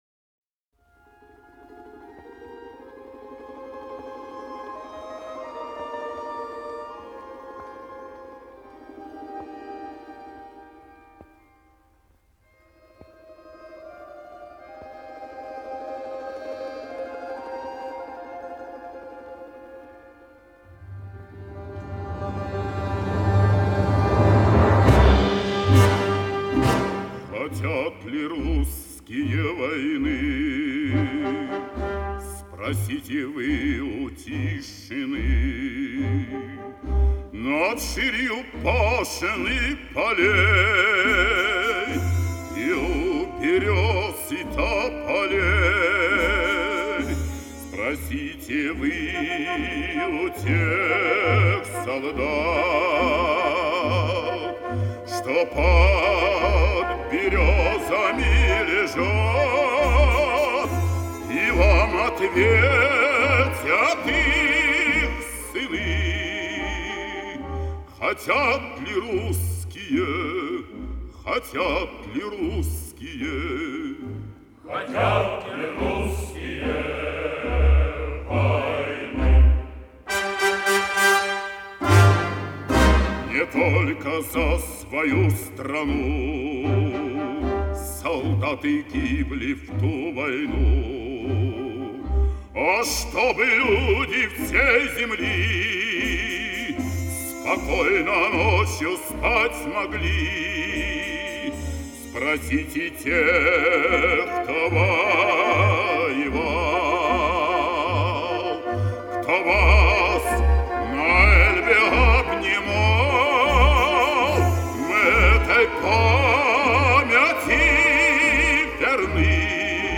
вокальный октет и оркестр